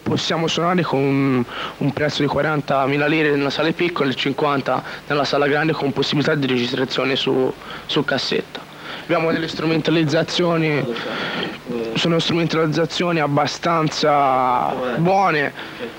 MusicaDroga Intervista.